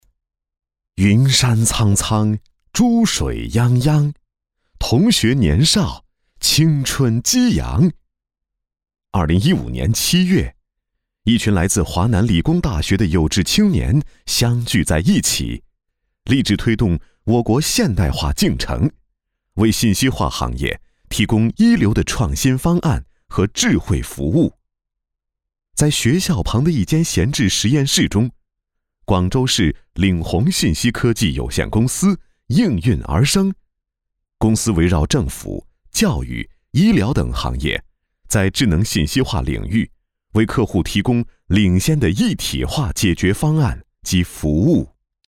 稳重磁性 企业专题
品质高端男声，声线磁性，声线比较自然。